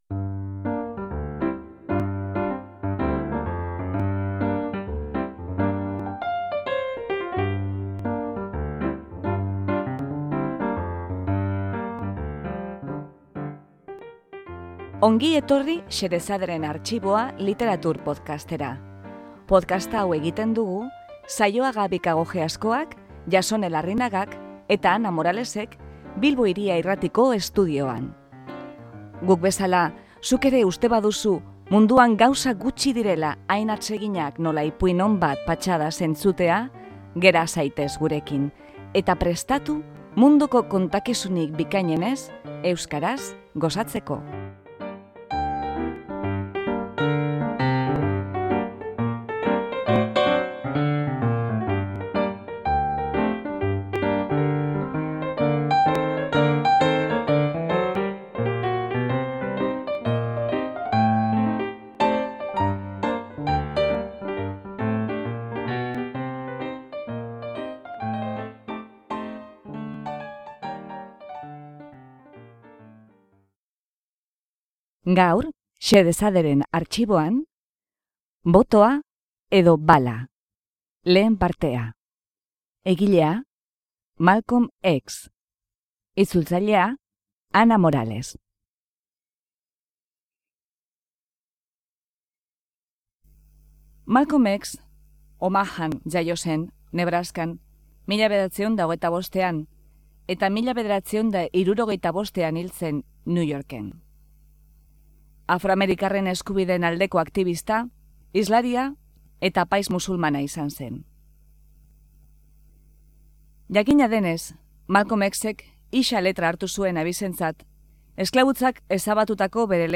Malcolm X aktibistak 1965ean, hil baino urtebe lehenago, emandako hitzaldia. Garai hartan, Senatuan Eskubide Zibilen Legea ari ziren eztabaidatzen eta, lege hori onartuz gero, beltz guztiek botoa emateko eskubidea lortuko lukete. Malcolm Xek boterean dauden demokraten joko bikoitza salatzen du eta beltzen batasunerako deia egiten du.